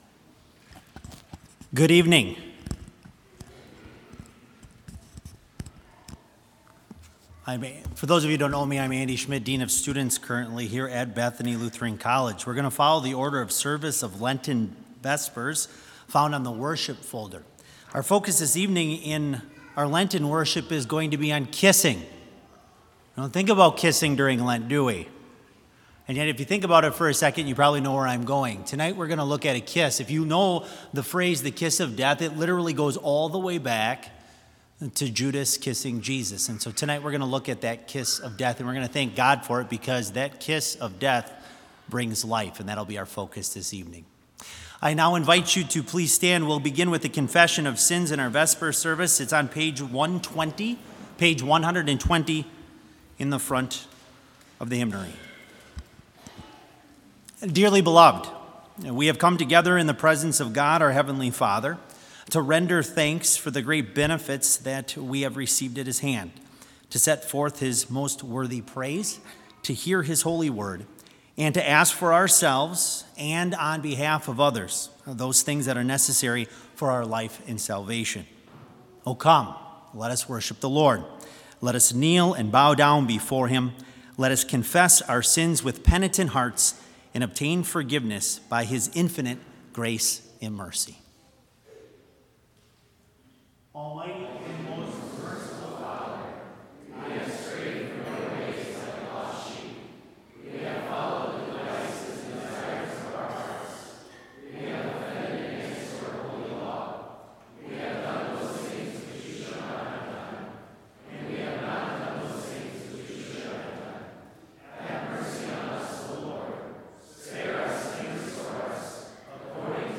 Complete service audio for Lent - March 4, 2020